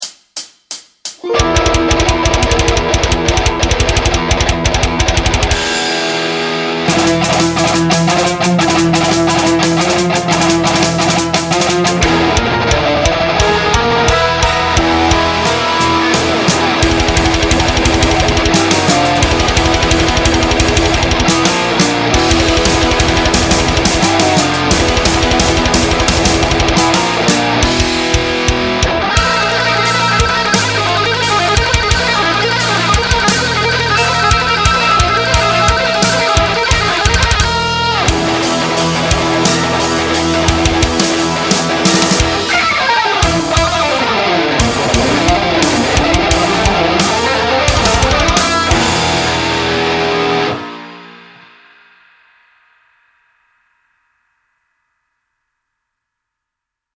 Everything you need to master metal guitar picking mechanics is in this etude. Gallop picking, tremolo picking, alternate picking and more.